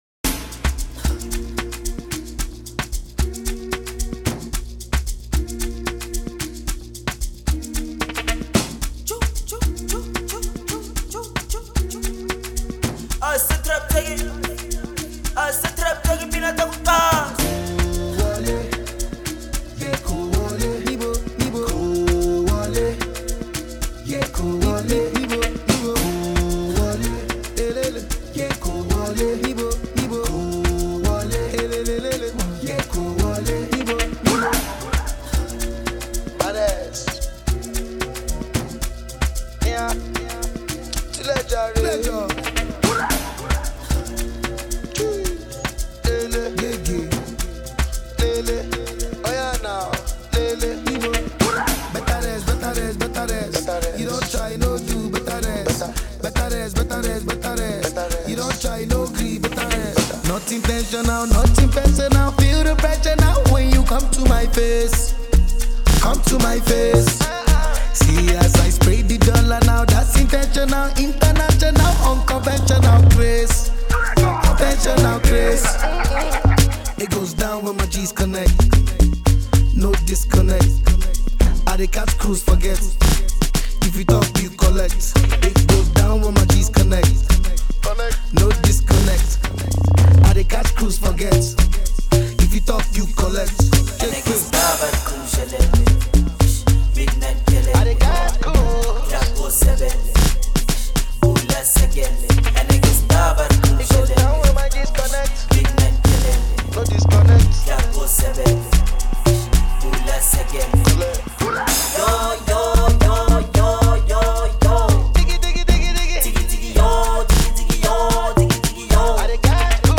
South African Amapiano virtuoso